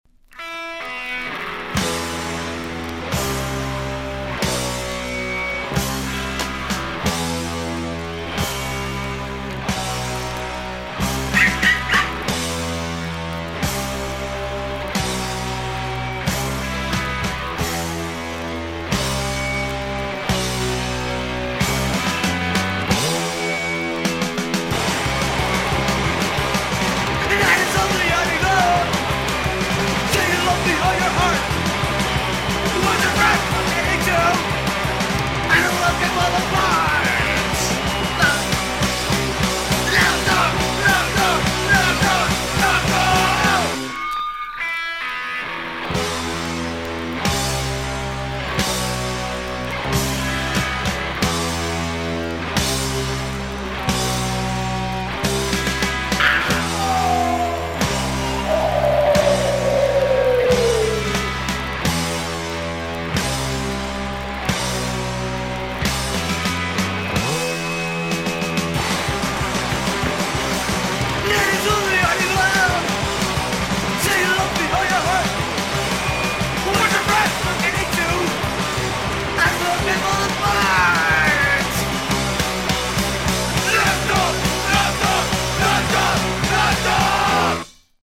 dharma-tinged HC